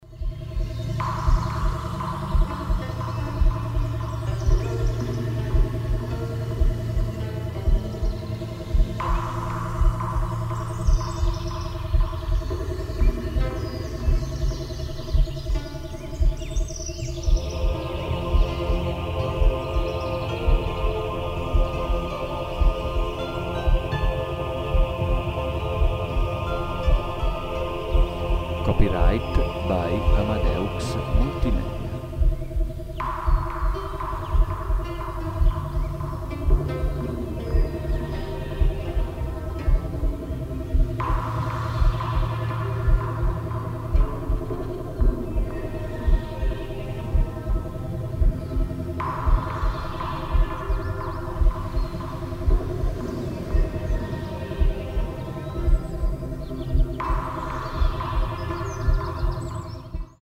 Armonizzazione Kundalini 432 Hz + ASMR